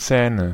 Ääntäminen
Ääntäminen Tuntematon aksentti: IPA: /ˈt͡sɛːnə/ Haettu sana löytyi näillä lähdekielillä: saksa Käännöksiä ei löytynyt valitulle kohdekielelle. Zähne on sanan Zahn monikko.